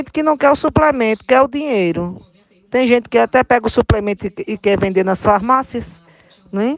Ouça o áudio da prefeita Magna Gerbasi:
audio-magna-gerbasi.mp3